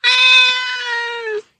猫の甘え声３
cat_sweet_voice3.mp3